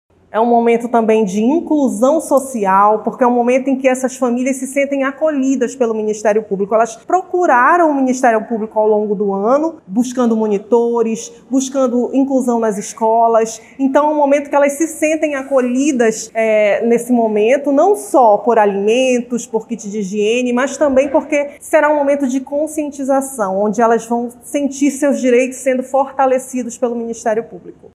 A Promotora de Justiça e Coordenadora do Centro de Apoio Operacional da Infância e Juventude (CAO-IJ), Romina Carvalho, destaca a relevância da ação e como a iniciativa beneficia as famílias.